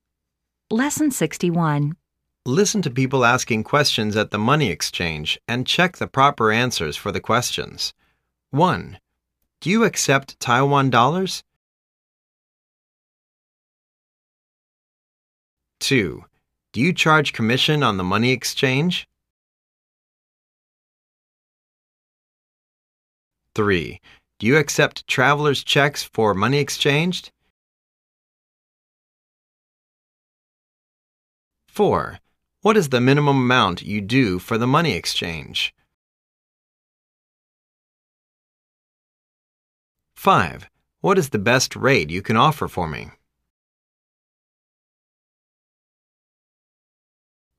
Listen to people asking questions at the money exchange and check the proper answers for the questions.